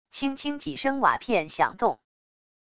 轻轻几声瓦片响动wav下载